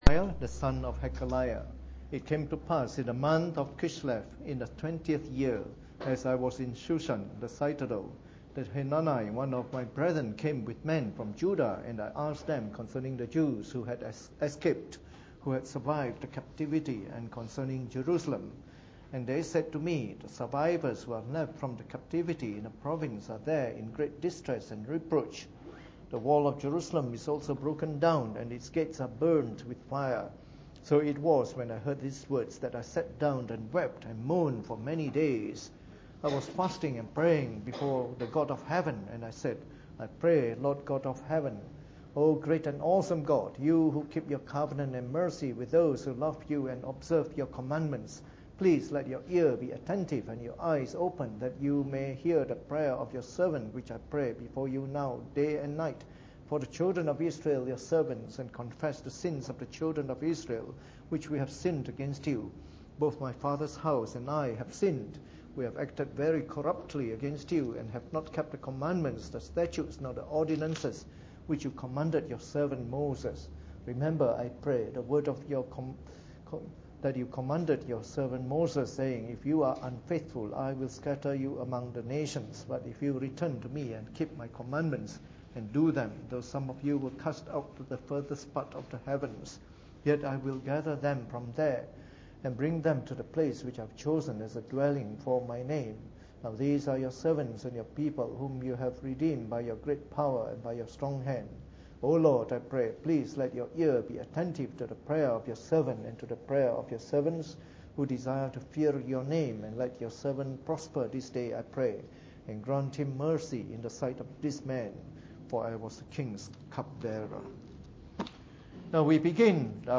Preached on the 16th of April 2014 during the Bible Study, the first talk in our series on the Book of Nehemiah.